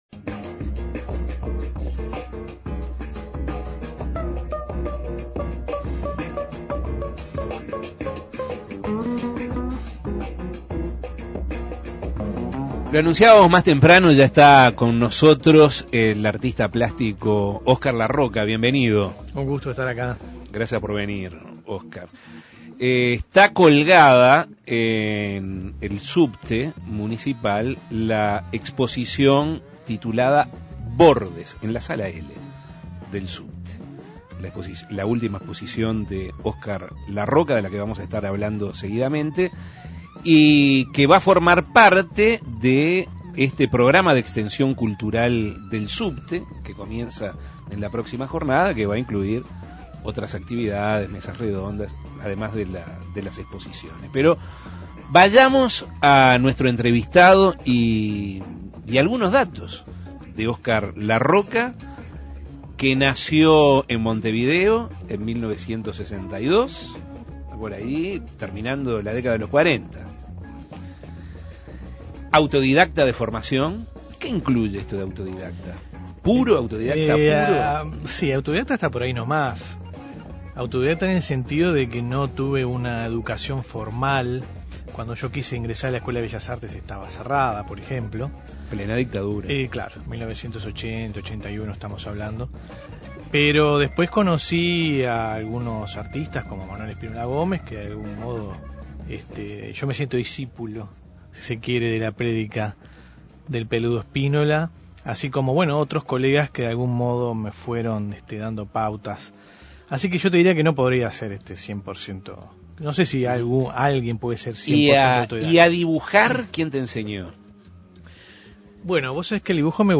Entrevistas Los Bordes del Subte Imprimir A- A A+ El próximo 24 de febrero a las 18 horas se inicia el Programa de Extensión Cultural del Subte.